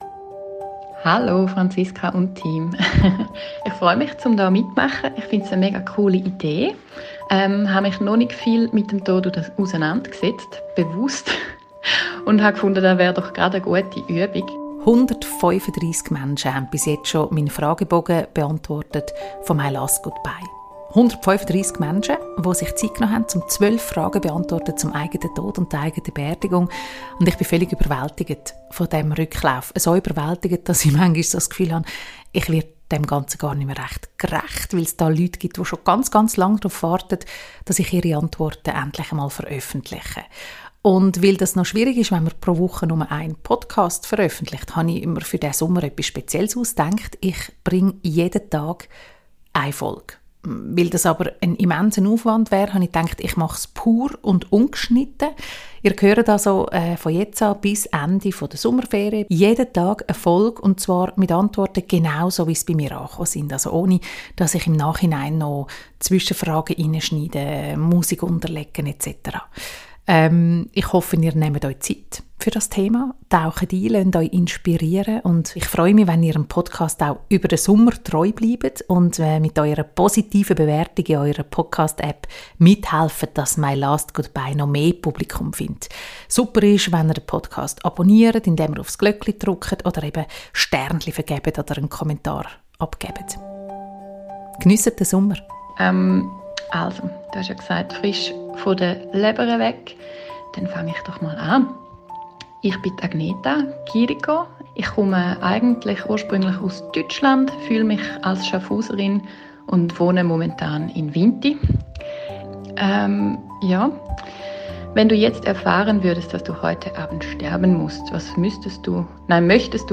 MY LAST GOOD BYE pur und ungeschnitten.
Ihr hört die Antworten auf meinen MY LAST GOODBYE-Fragen genau so, wie sie via Whatsapp-Sprachmessage bei mir gelandet sind.